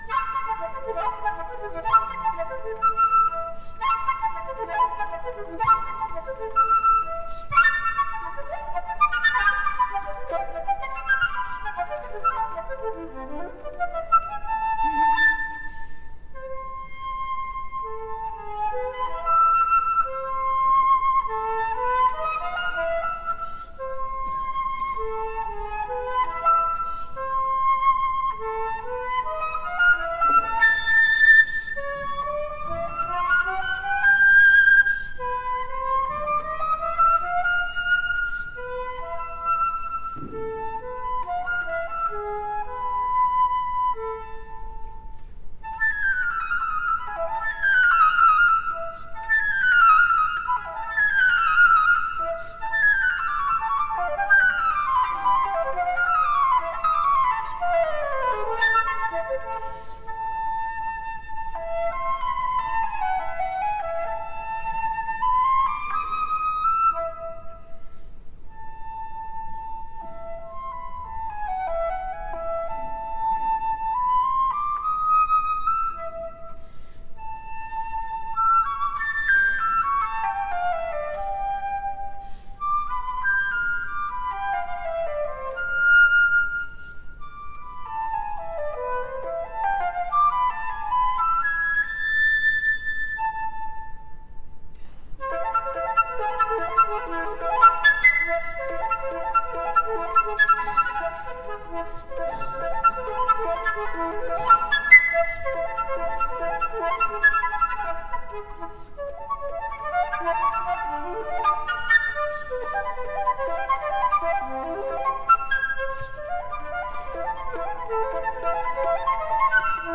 solo flute - KUPETSKY (Johann), le joueur de flute taversiere.mp3